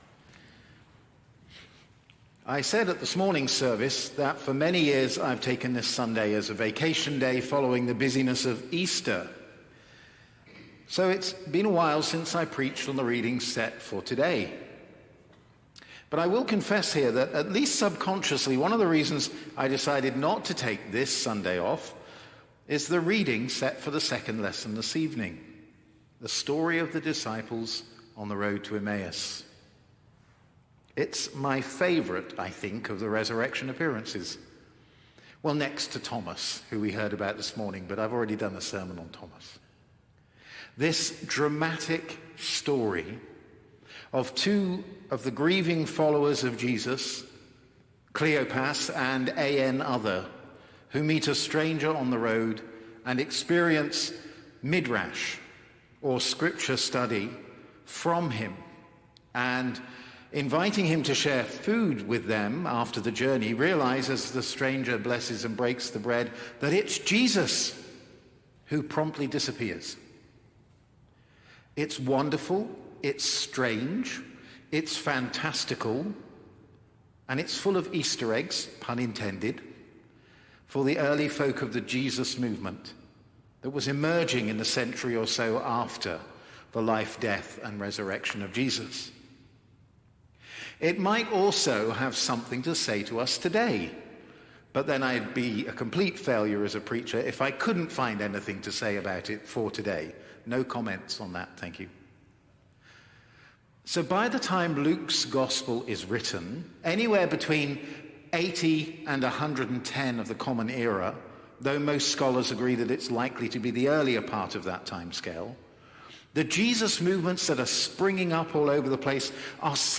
Sermons | St. John the Divine Anglican Church